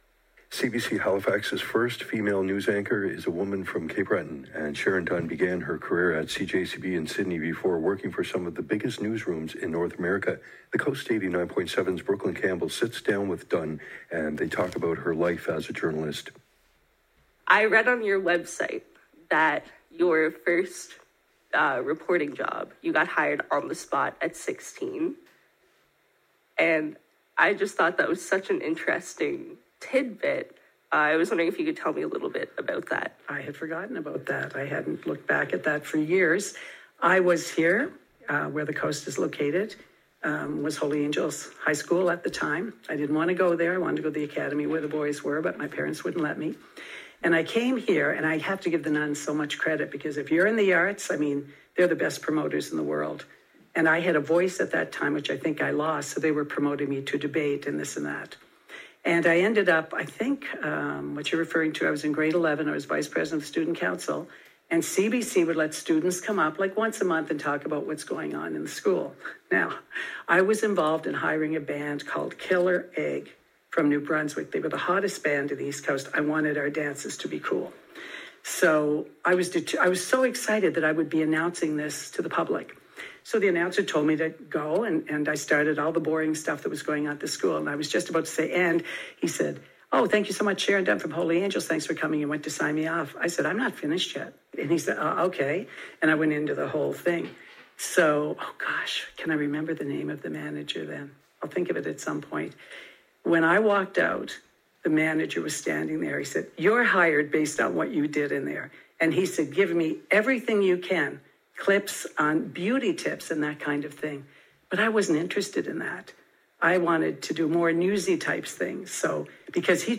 Hi Everyone! Check out my recent radio interview above.